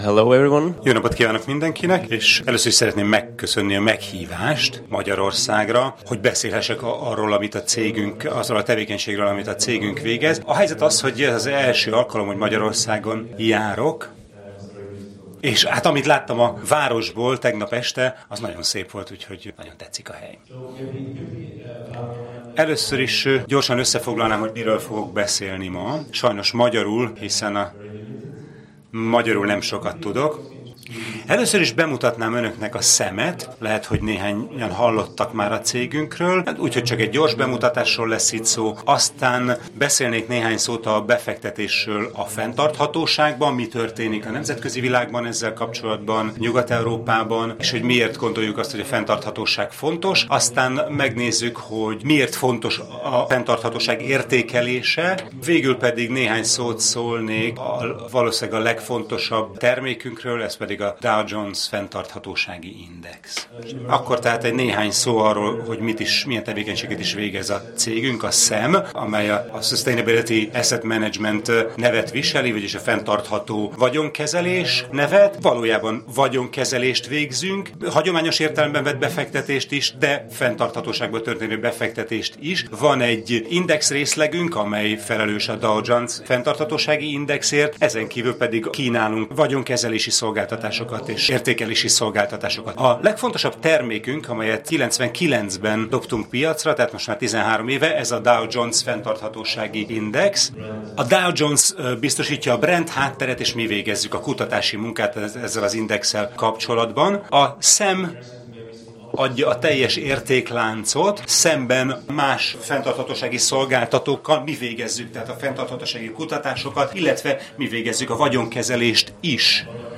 A 2012-es CSR Hungary Konferenciánkon